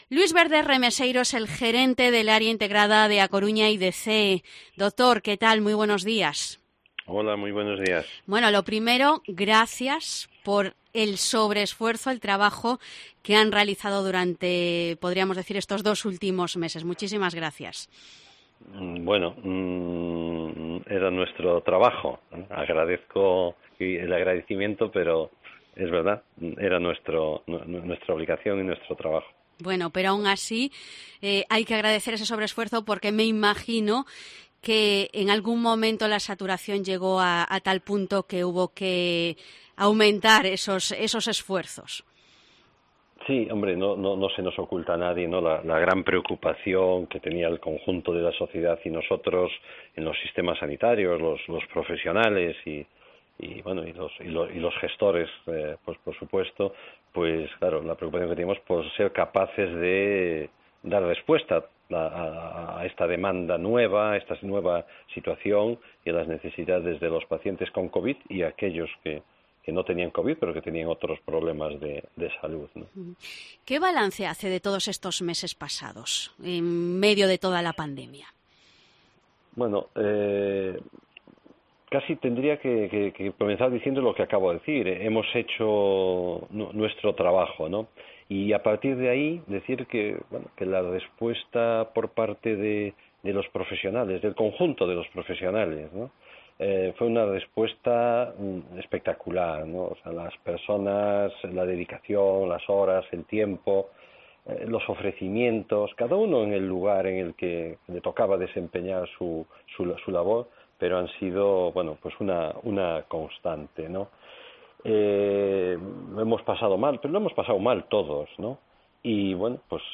Entrevista a Luis Verde, gerente del área sanitaria de A Coruña y Cee